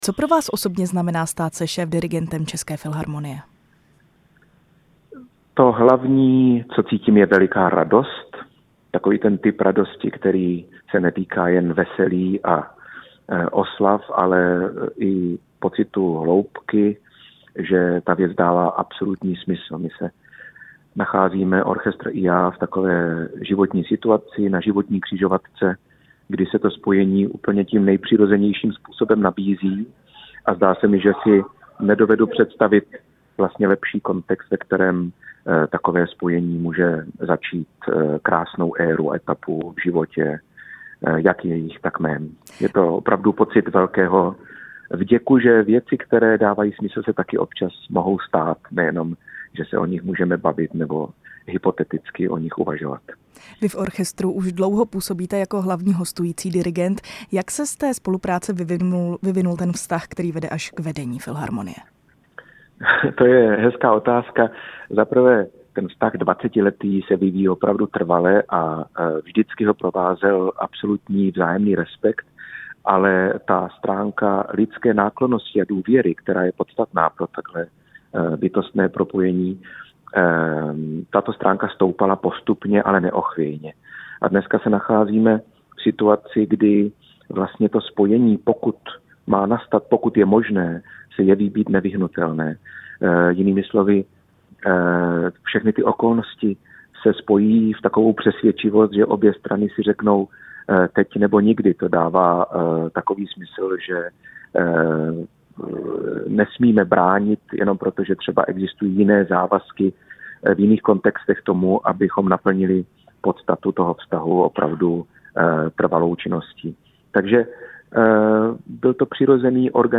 O uměleckých vizích, české hudbě i roli orchestru ve společnosti jsme s dirigentem mluvili ve vysílání Rádia Prostor.
první část rozhovoru s dirigentem Jakubem Hrůšou